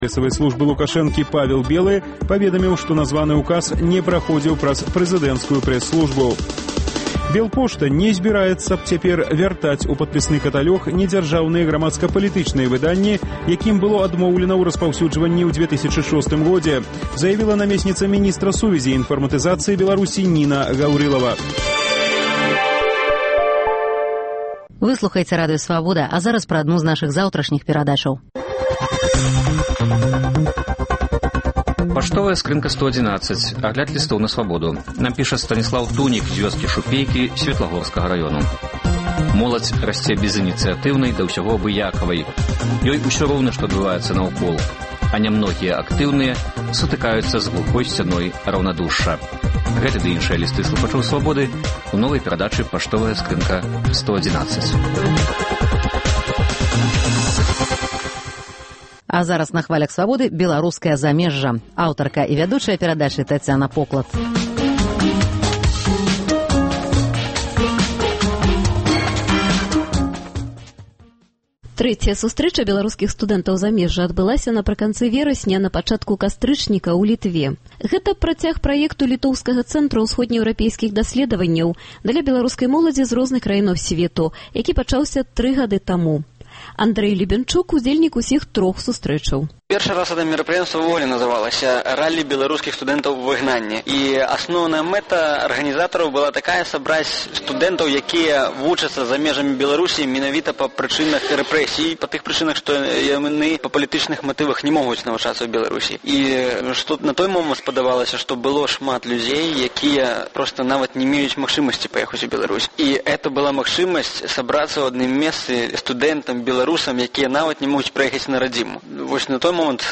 Для чаго студэнты зьбіраюцца на гэтыя сустрэчы і чым адметны сёлетні з'езд - у новай перадачы Беларускае замежжа распавядаюць студэнты з Эўропы і Амэрыкі.